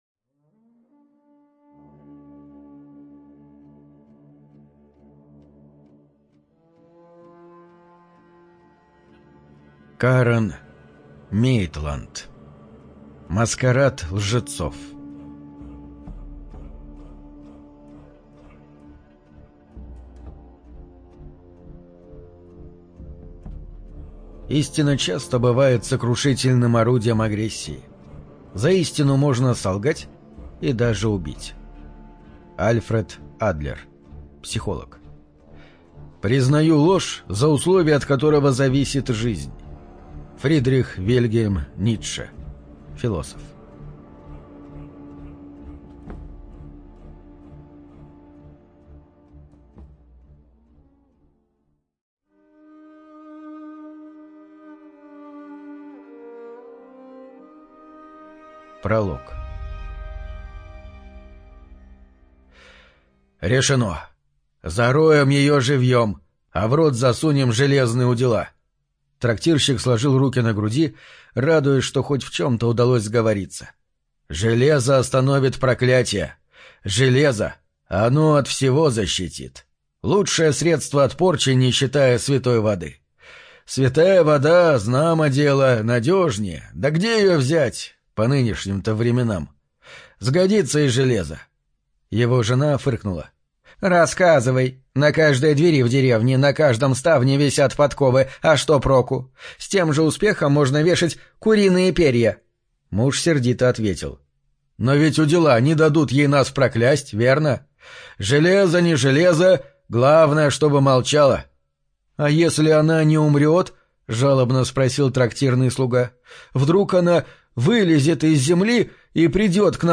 ЖанрДетективы и триллеры, Приключения, Ужасы и мистика